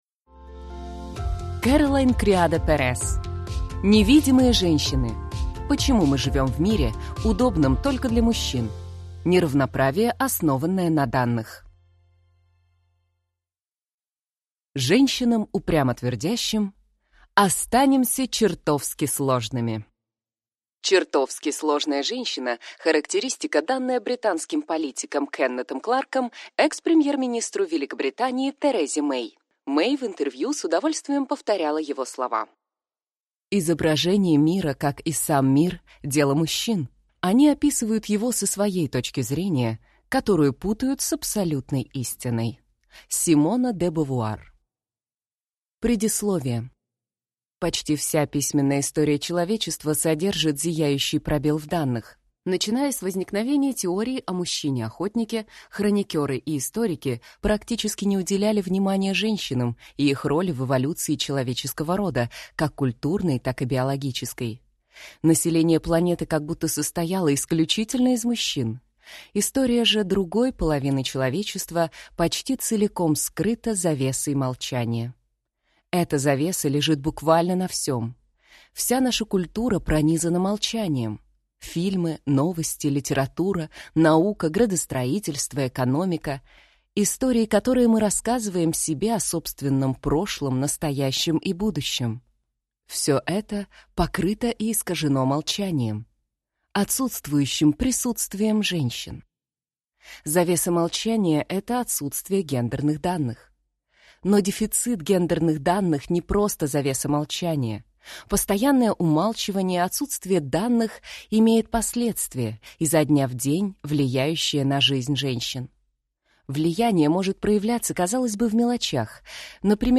Аудиокнига Невидимые женщины. Почему мы живем в мире, удобном только для мужчин. Неравноправие, основанное на данных | Библиотека аудиокниг